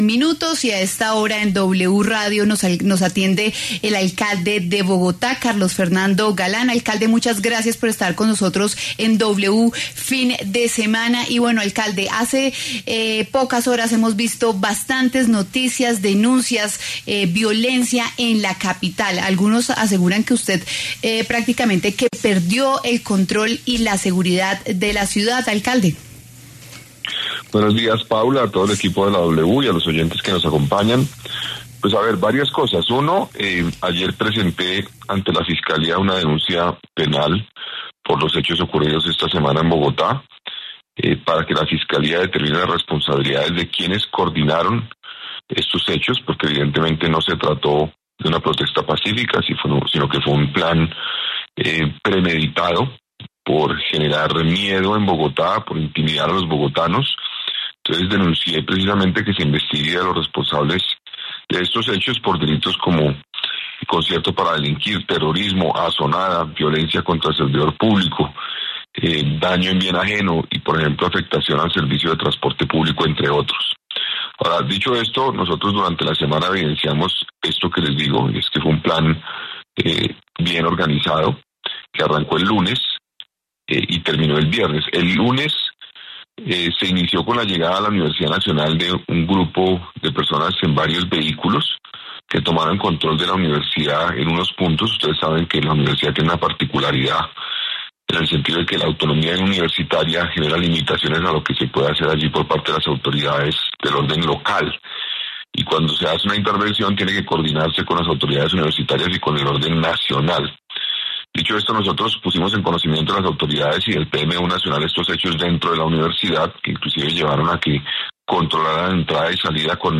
Carlos Fernando Galán, alcalde de Bogotá, habló en W Fin de Semana sobre las jornada de manifestaciones, bloqueos y enfrentamientos ocurridos en la capital en los últimos días.